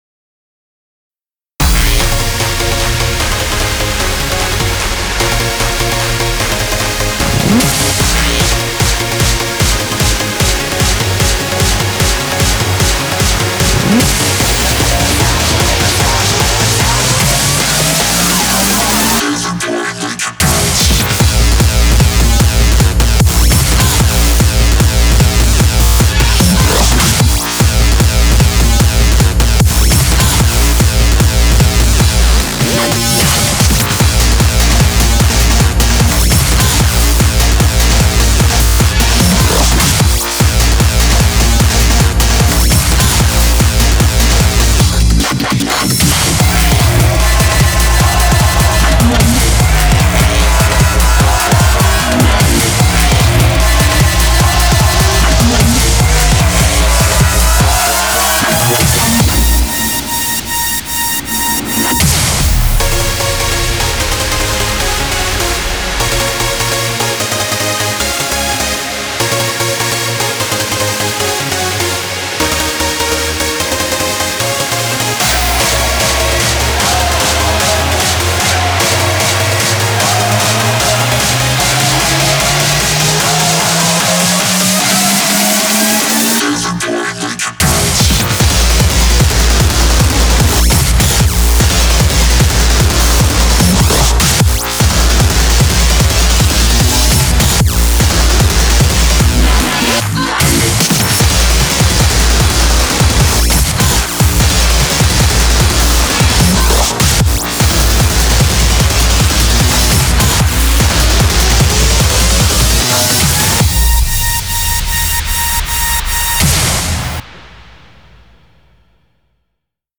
BPM38-300
Audio QualityPerfect (High Quality)
TIP: Main BPM is 150.